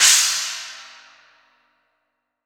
Index of /90_sSampleCDs/AKAI S6000 CD-ROM - Volume 3/Drum_Kit/DRY_KIT2